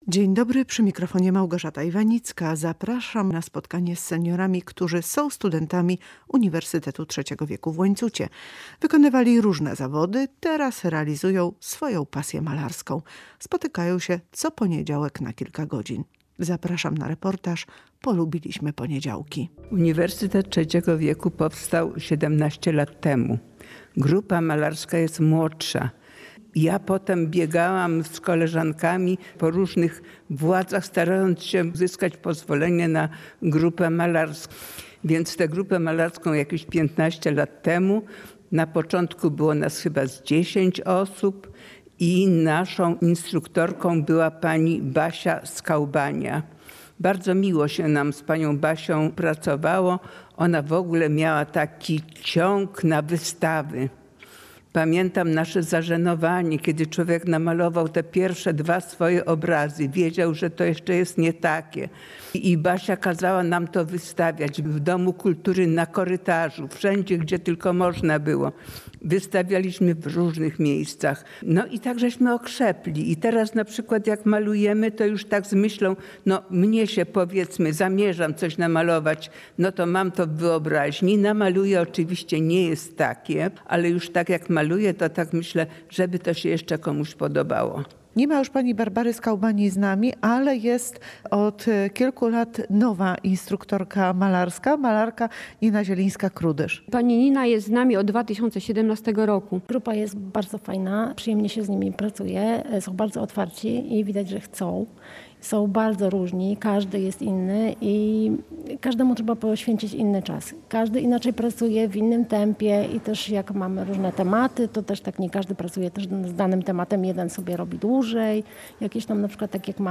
Zapraszam na reportaż „Polubiliśmy poniedziałki”.